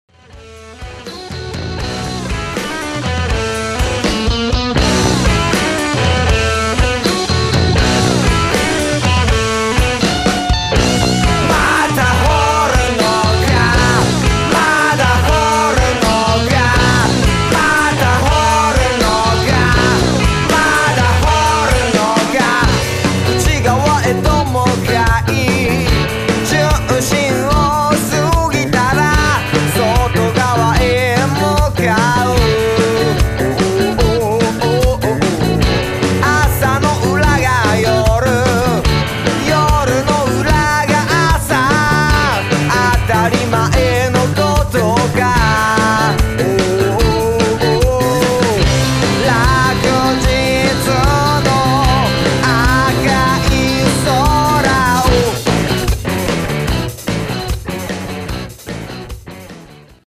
シンプルかつ明解なブルースロックバンド。
演奏力もあるし、ボーカルも説得力あるし、なかなか魅力的なバンドです。
これまたオーソドックスというか、70年代のロックバンドを思わせる演奏ですね。